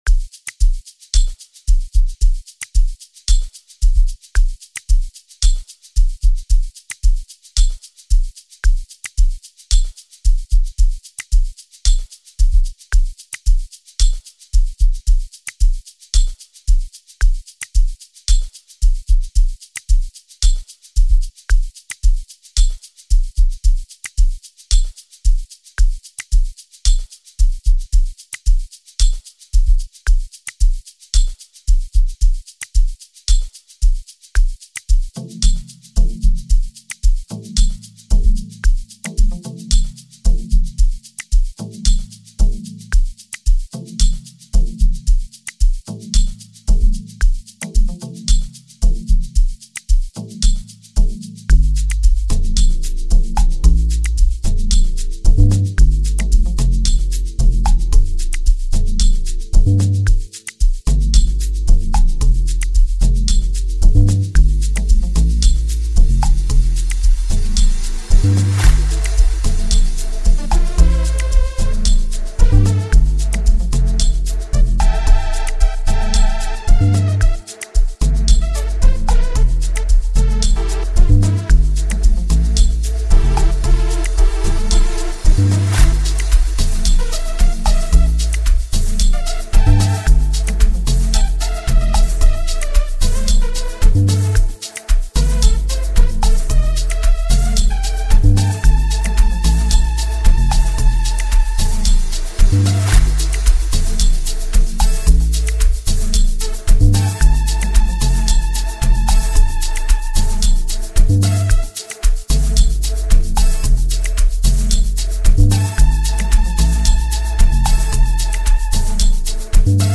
Amapiano
With its infectious beat and captivating rhythm
and let the vibrant beats and melodies transport you.